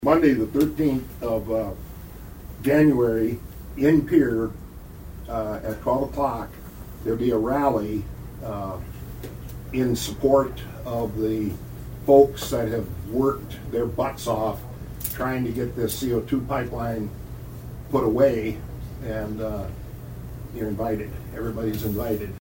Feickert inform the commission about an upcoming rally prior to the start of the 2025 legislative session.